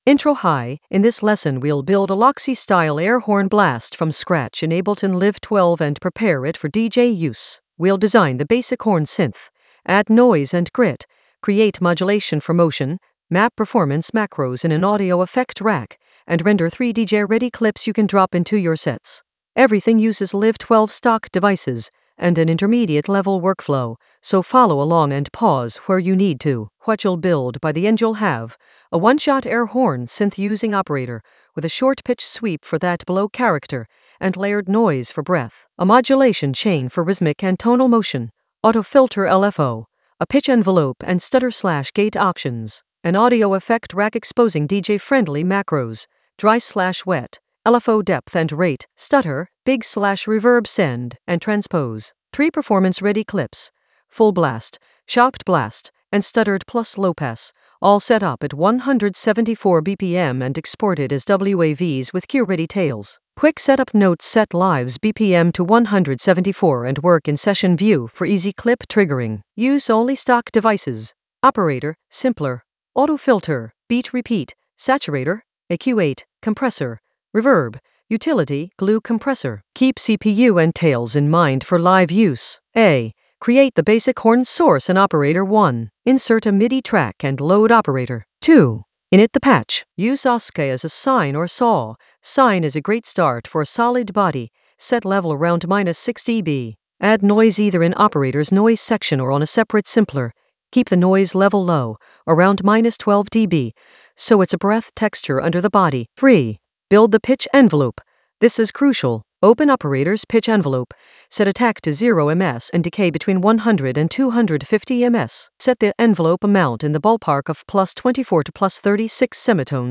An AI-generated intermediate Ableton lesson focused on Loxy edit: modulate an air horn blast from scratch in Ableton Live 12 with DJ-friendly structure in the DJ Tools area of drum and bass production.
Narrated lesson audio
The voice track includes the tutorial plus extra teacher commentary.